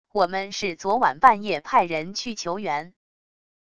我们是昨晚半夜派人去求援wav音频生成系统WAV Audio Player